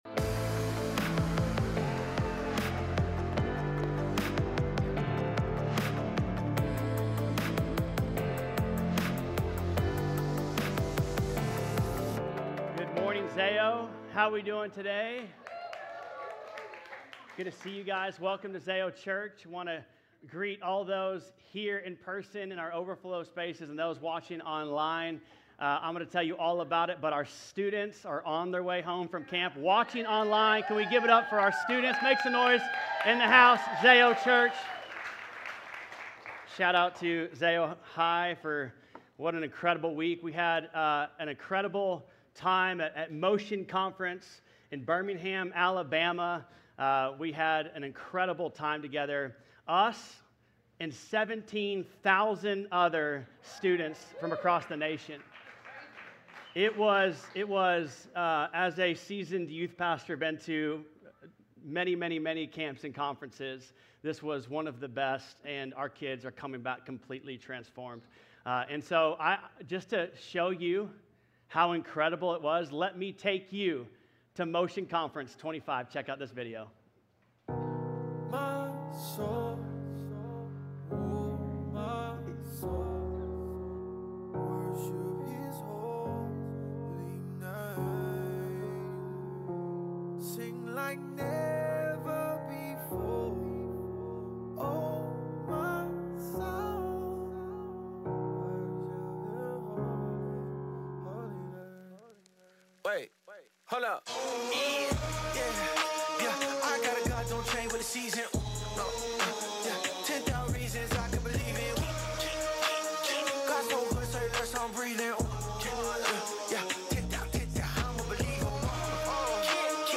7_27_25+sermon+audio.mp3